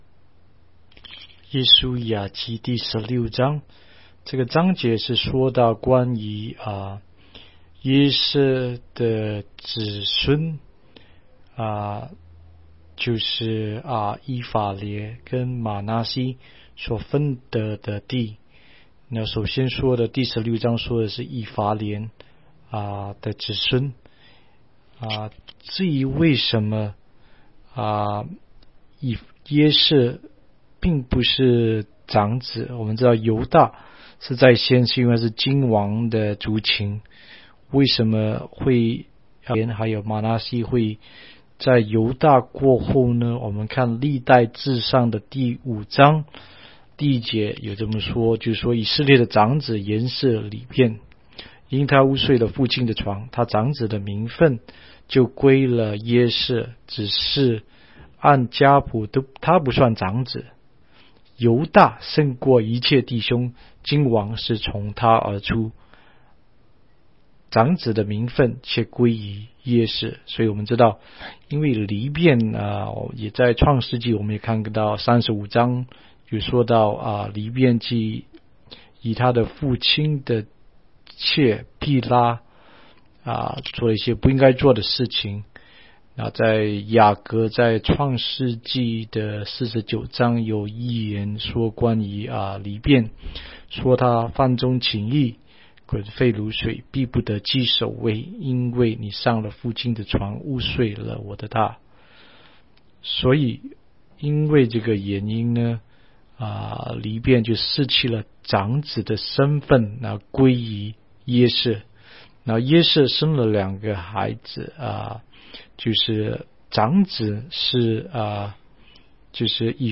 16街讲道录音 - 每日读经-《约书亚记》16章